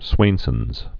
(swānsənz)